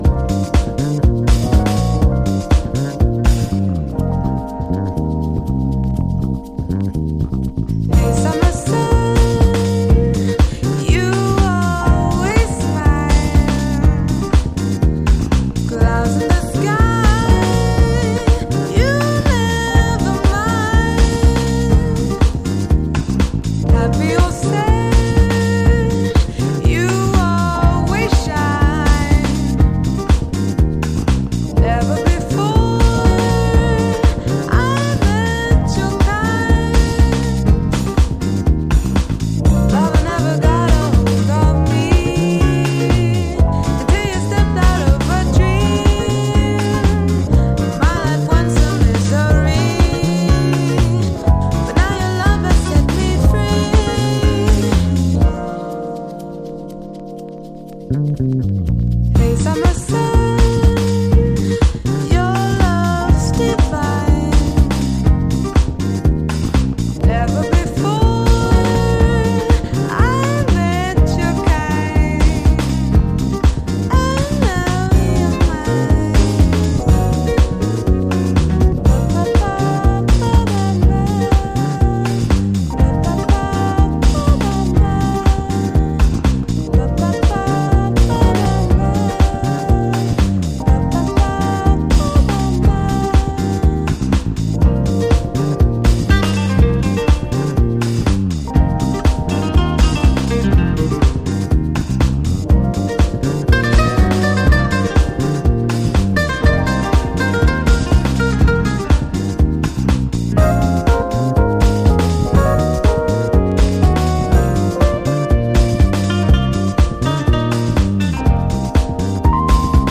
BASS
BONGOS
FLUTE, BRASS [REEDS]
VIBRAPHONE